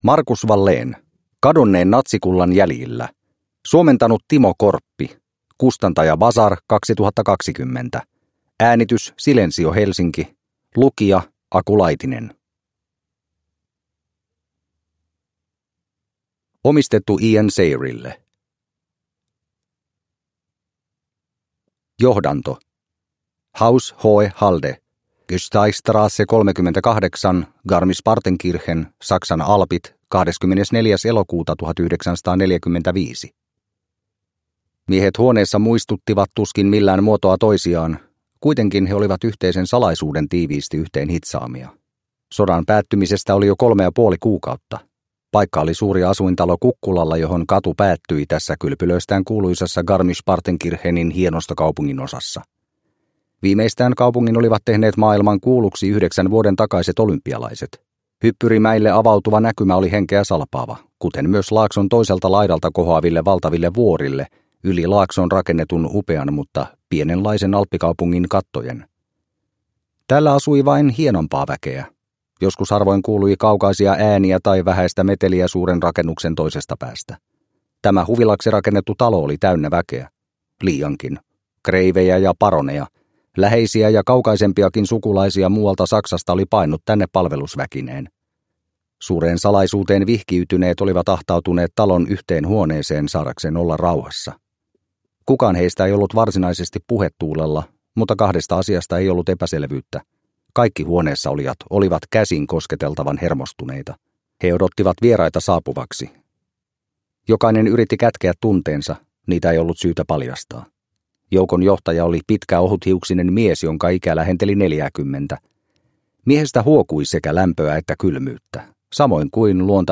Kadonneen natsikullan jäljillä – Ljudbok – Laddas ner